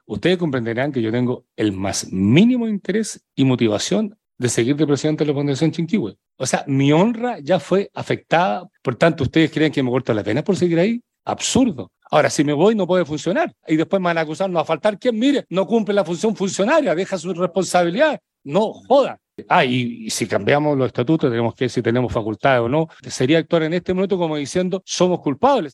Durante la última sesión del Consejo Regional, la máxima autoridad regional manifestó sentirse afectado por las acusaciones y denuncias sobre los cuestionados traspasos de recursos hacia la entidad privada.